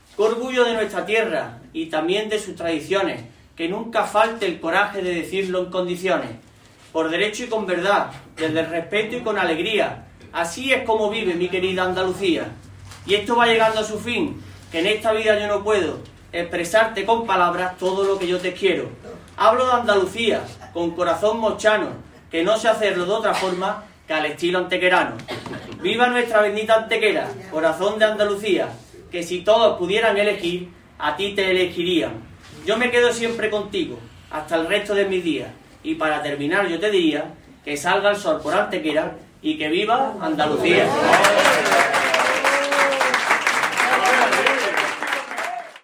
El concejal José Manuel Fernández fue el encargado de pregonar el Día de Andalucía, acto también tradicional que se realiza en los prolegómenos de dicha merienda de convivencia.
Cortes de voz